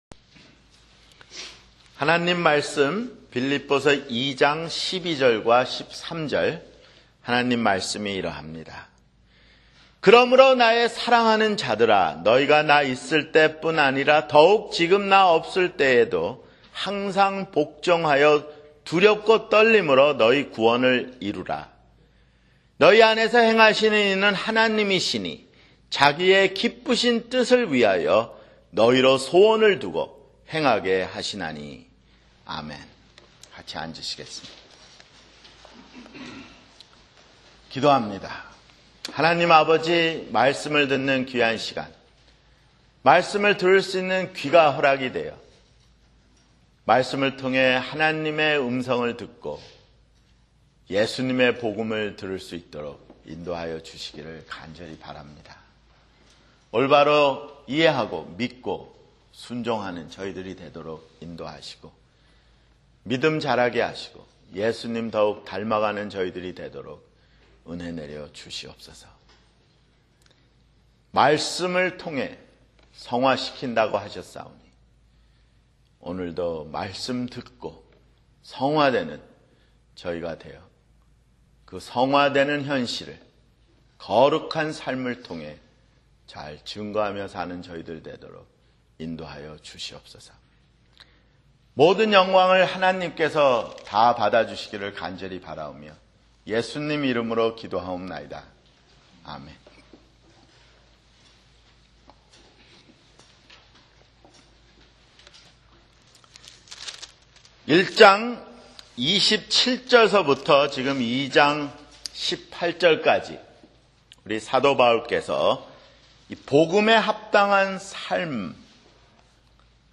[주일설교] 빌립보서 (31)